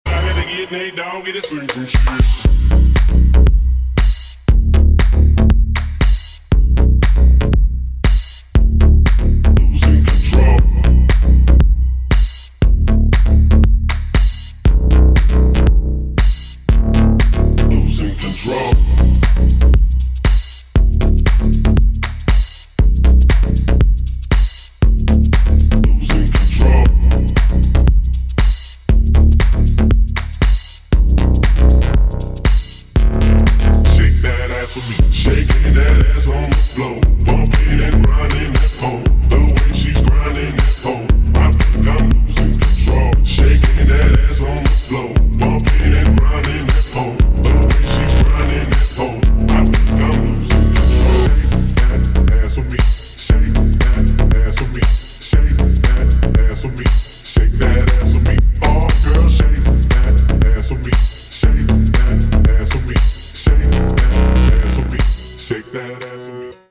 deep house
мощные басы
Bass House
качающие
G-House
низкий мужской голос